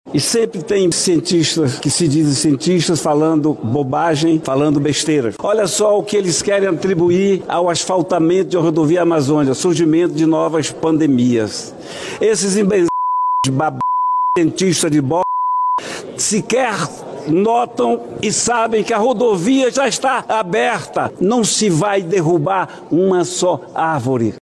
Durante discurso no Senado, o senador disse que as informações são ‘manipuladoras e hipócritas”, que impedem o avanço da rodovia.